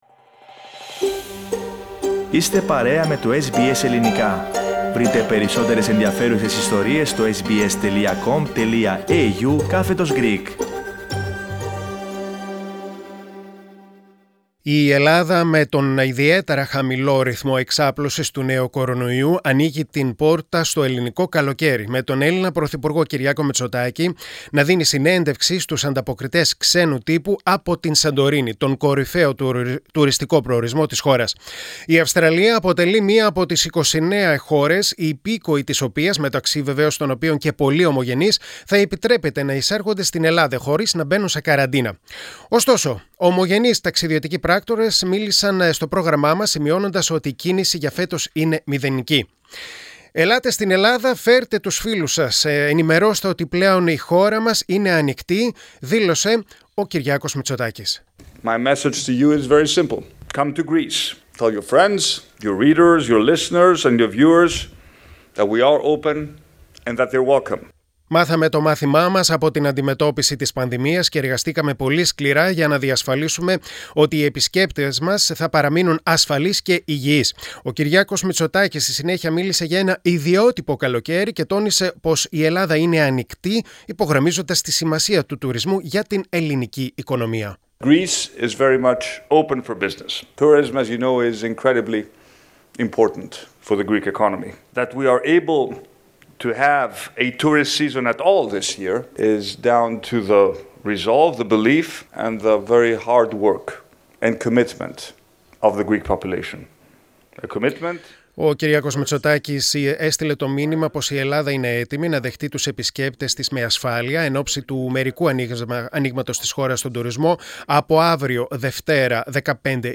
Ελληνοαυστραλοί ταξιδιωτικοί πράκτορες μιλούν στο SBS Greek για την πορεία των φετικών κρατήσεων για τα ταξίδια των ομογενών στην Ελλάδα.